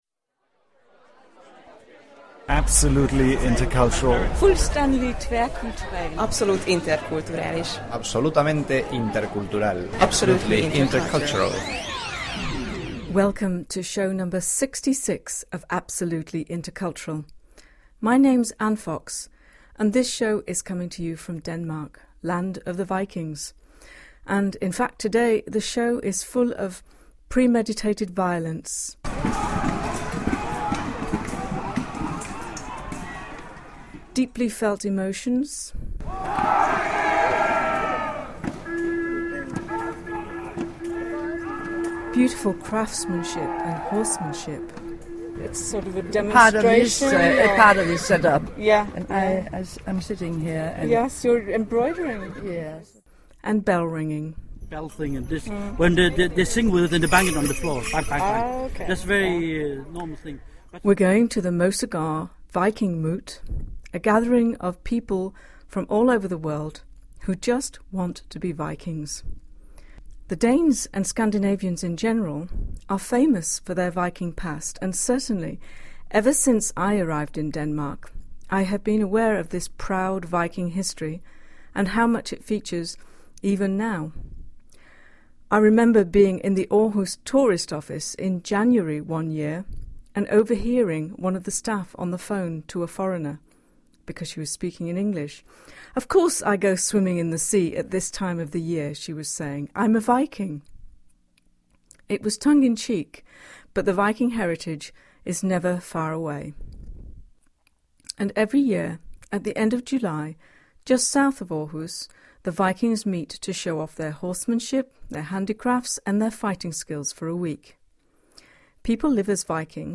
We’re going to the Mosegaard Viking Moot , a gathering of people from all over the world who just want to be Vikings.